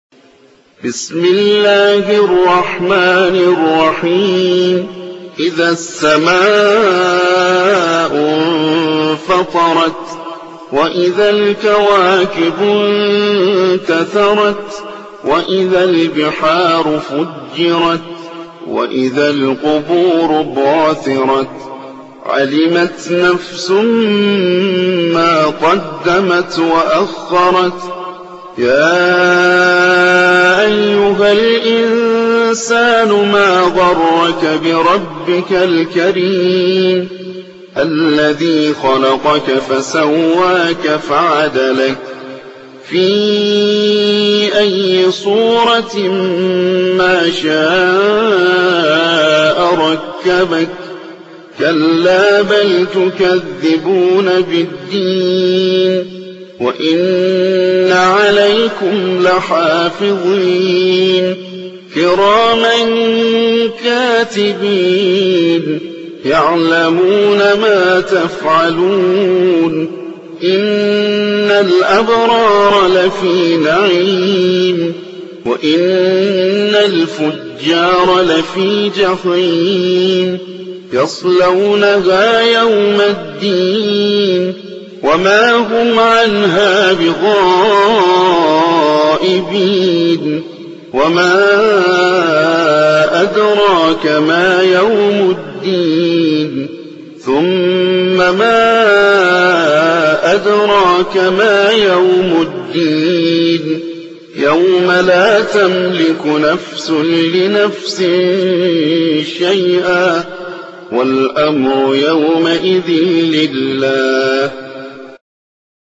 82. سورة الانفطار / القارئ